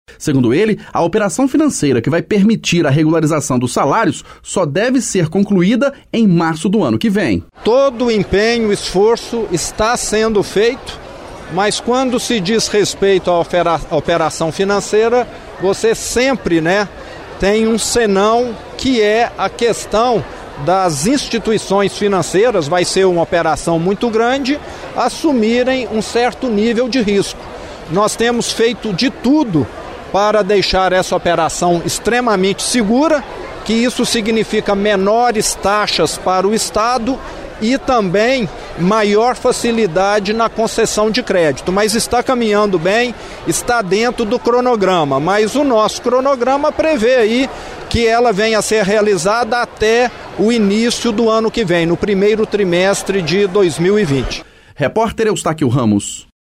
As declarações foram dadas durante o lançamento da marca de Minas – identidade visual do turismo do estado – em cerimônia no Palácio da Liberdade, na região Centro-Sul de Belo Horizonte.